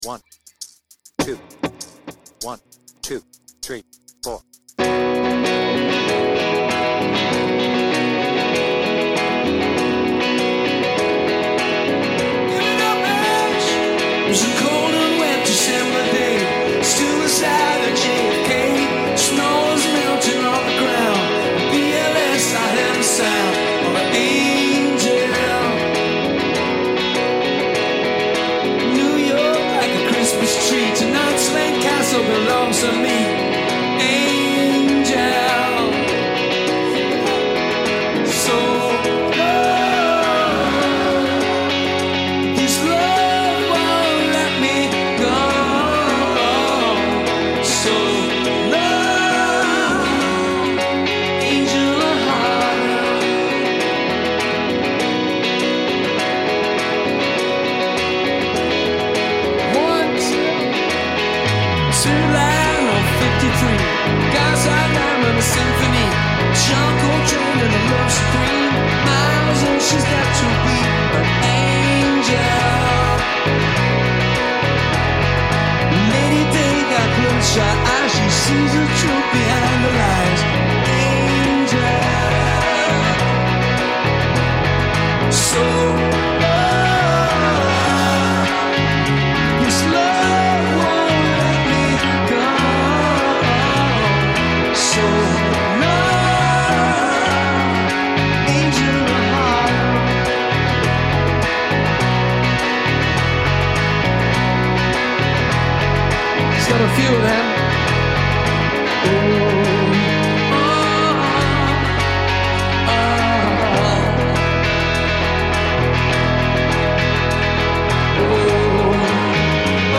BPM : 102
Tuning : Eb
With Vocals